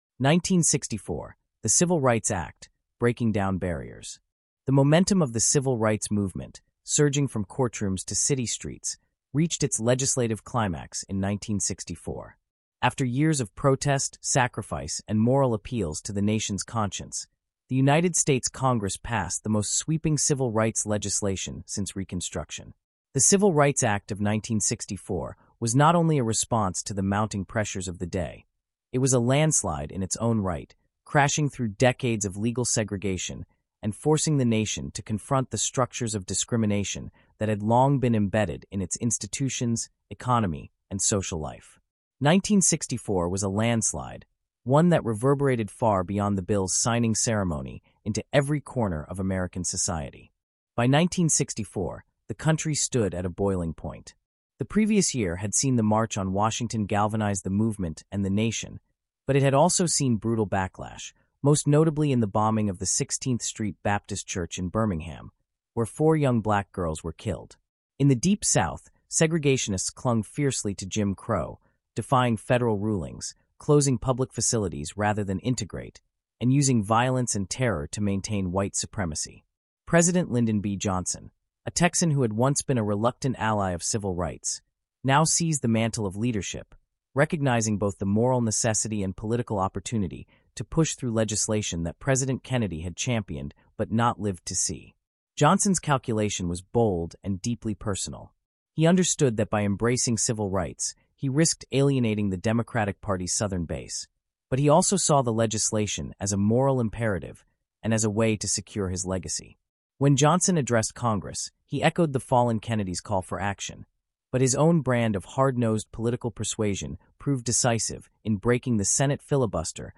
Disclosure: This podcast includes content generated using an AI voice model.